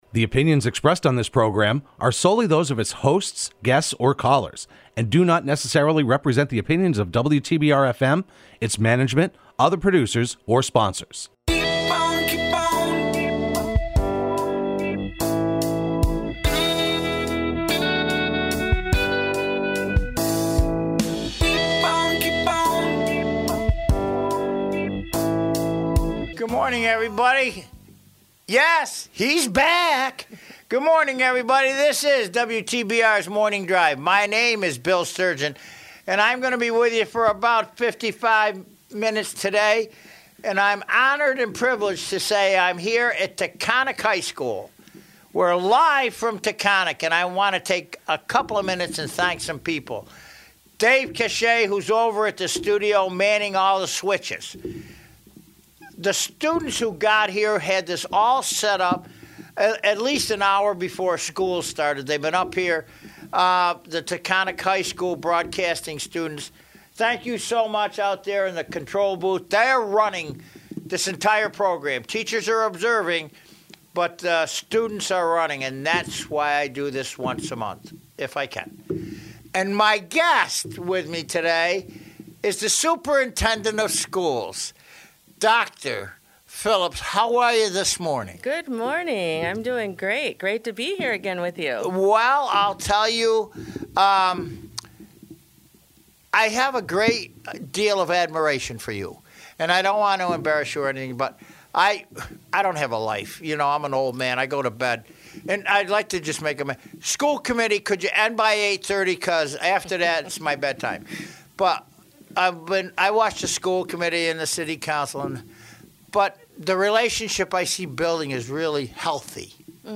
live from Taconic High School